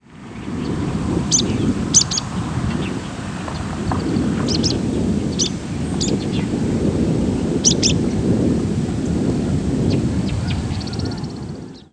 Sprague's Pipit diurnal flight calls
Bird in flight giving single and paired calls after being flushed.